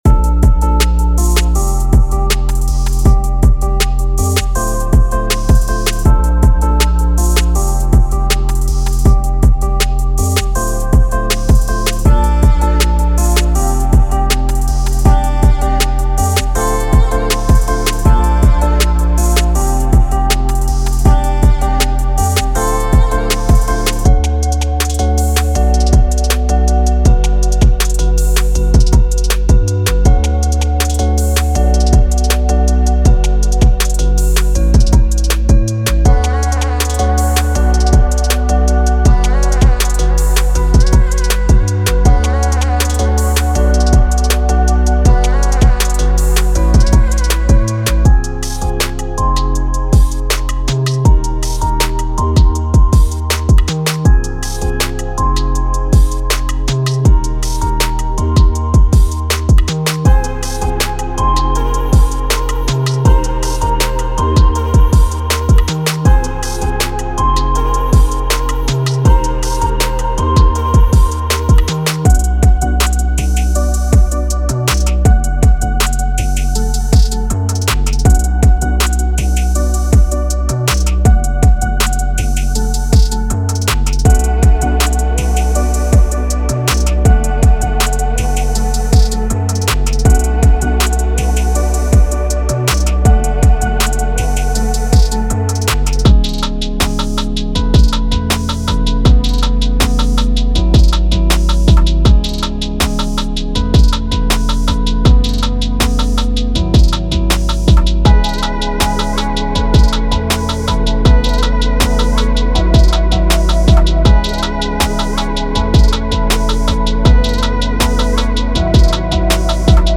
Crafted by seasoned producers and sound designers, this meticulously curated collection delivers 88 premium loops designed to infuse your tracks with contemporary energy and urban flair.
Explore a fusion of modern pop melodies with hard-hitting trap rhythms, perfect for creating chart-topping hits across various genres including pop, hip-hop, R&B, and more.